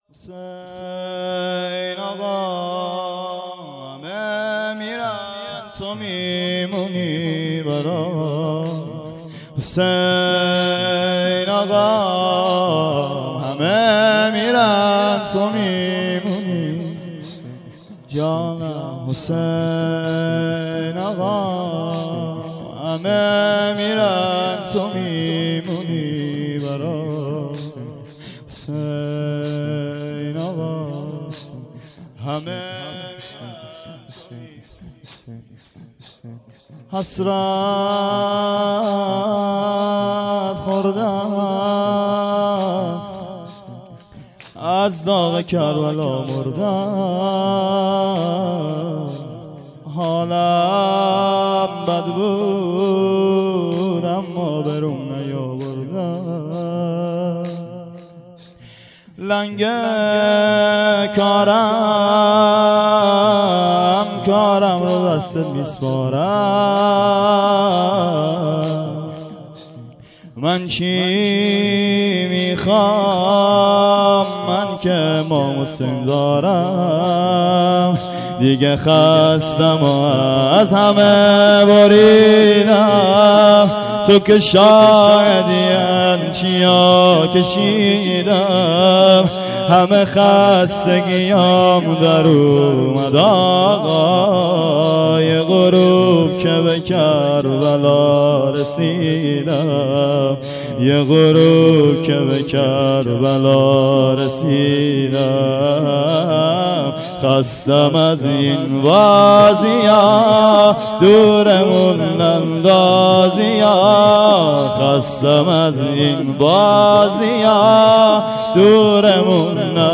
مراسم شهادت امام صادق علیه السلام اردیبهشت ۱۴۰۴